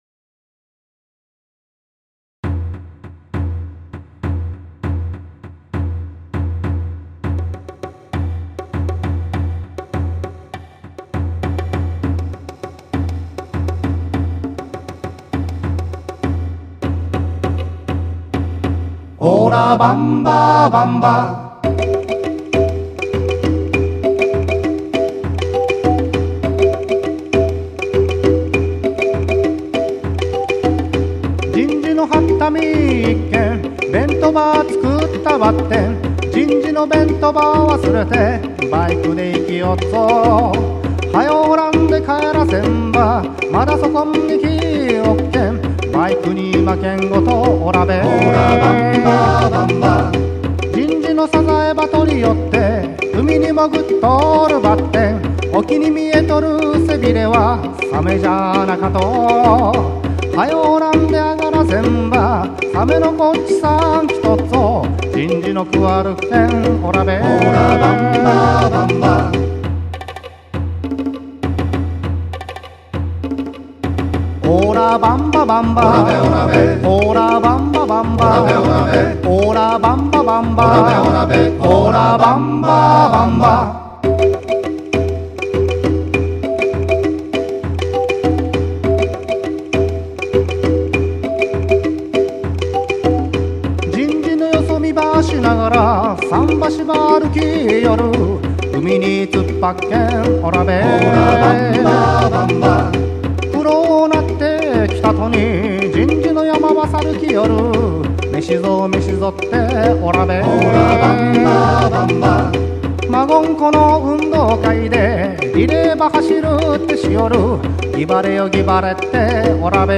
愛・地球博で買った「指ピアノ」は、こっちの方が聞こえると思います。
左側に聞こえる、リズムが不安定な音がそうです・・・って、それだけじゃ分からないか（笑）
通称「指ピアノ」を使って、自然をイメージして作りました。